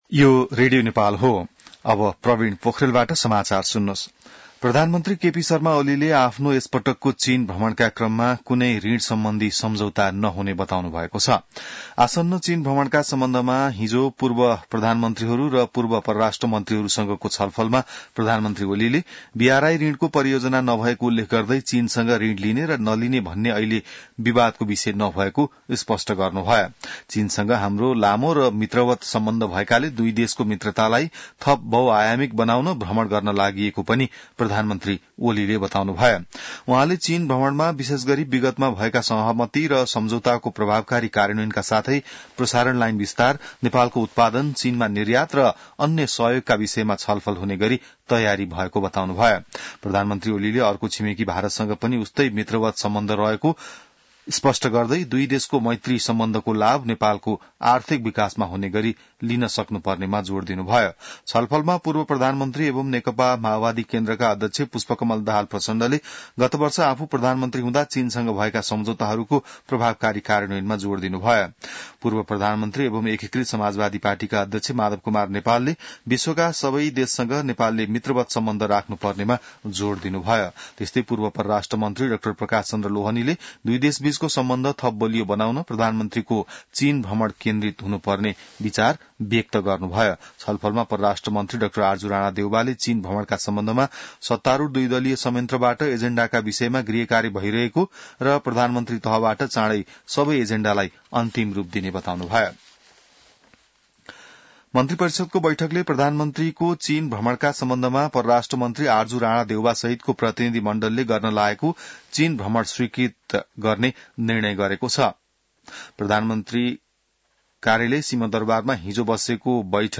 बिहान ६ बजेको नेपाली समाचार : १२ मंसिर , २०८१